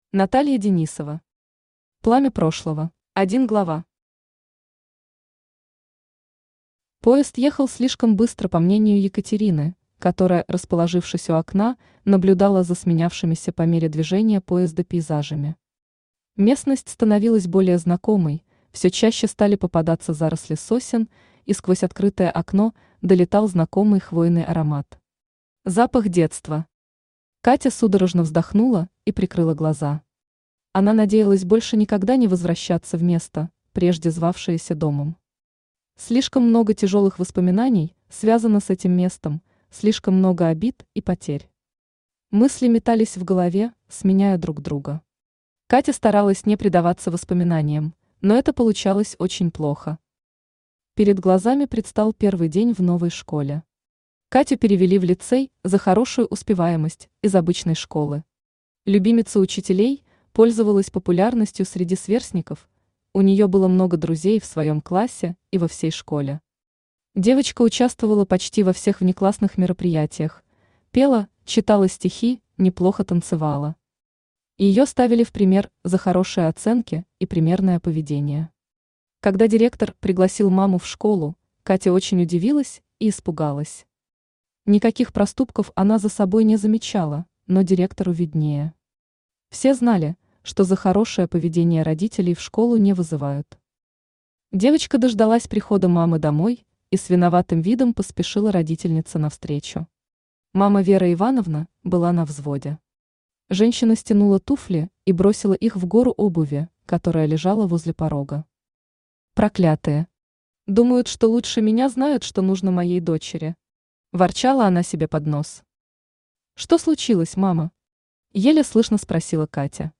Аудиокнига Пламя прошлого | Библиотека аудиокниг
Aудиокнига Пламя прошлого Автор Наталья Денисова Читает аудиокнигу Авточтец ЛитРес.